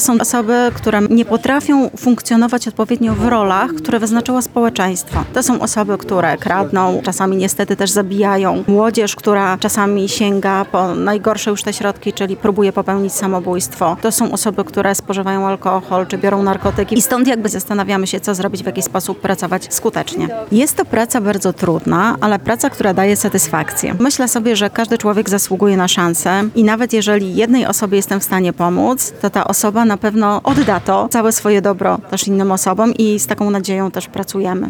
mówi w rozmowie z Radiem Lublin jedna z organizatorek wydarzenia